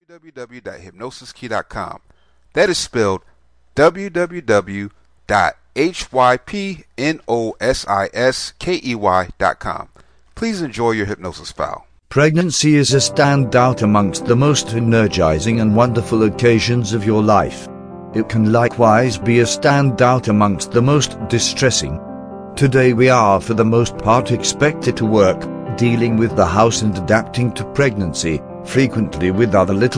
Perfect Pregnancy Visualization Self Hypnosis Mp3